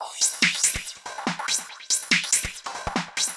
Index of /musicradar/uk-garage-samples/142bpm Lines n Loops/Beats
GA_BeatAFilter142-15.wav